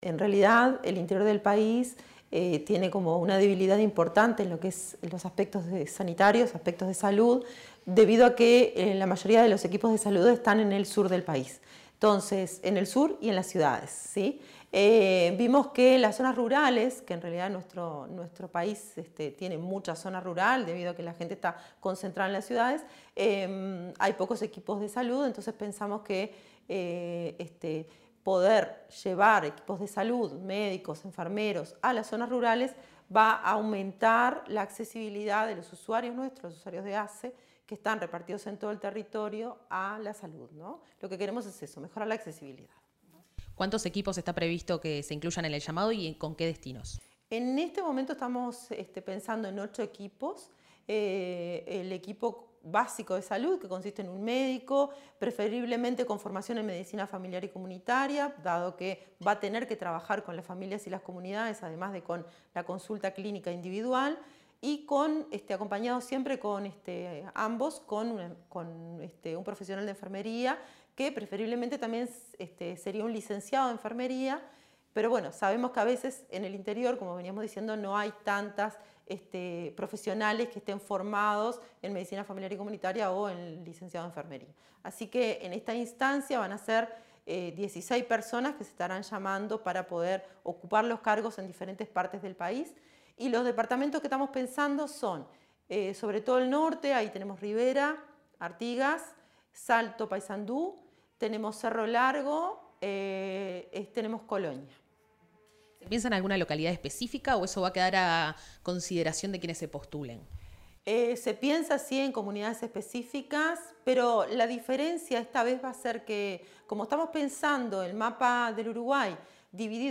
Declaraciones de la vocal de ASSE Marcela Cuadrado